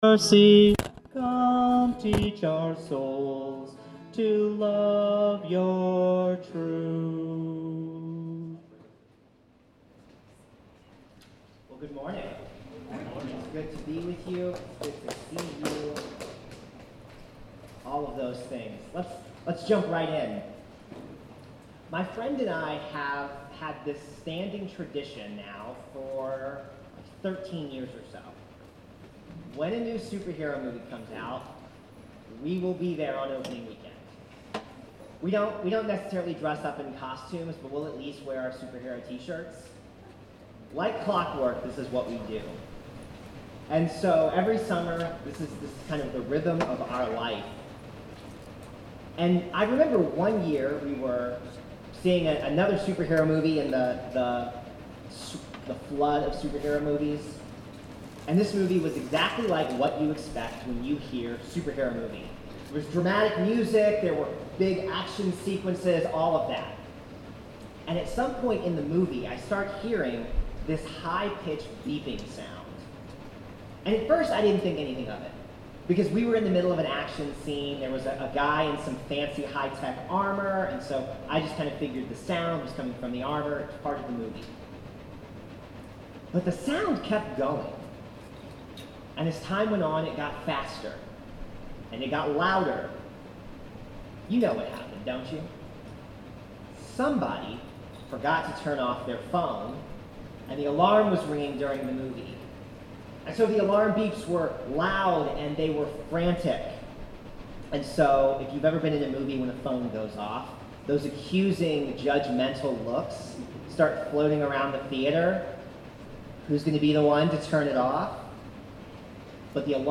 Sermons | Hope Presbyterian Church of Crozet
Guest Speaker